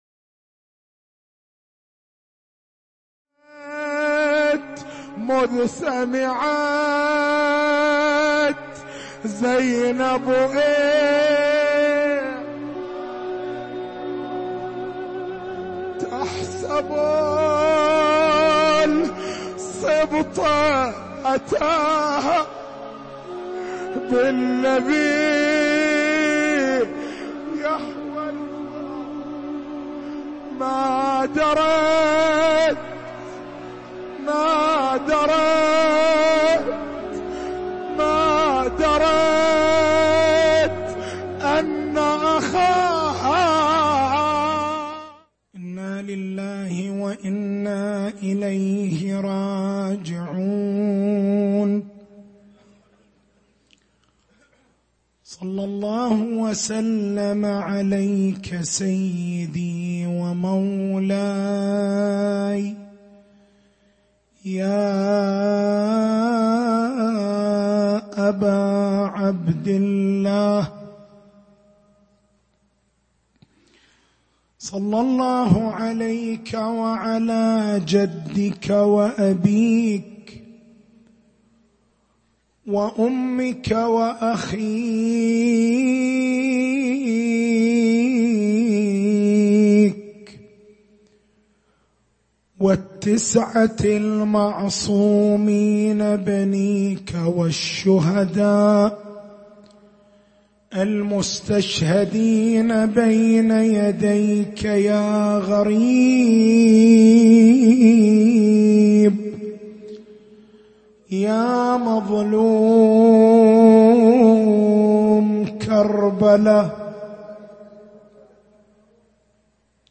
تاريخ المحاضرة
حسينية الزين بالقديح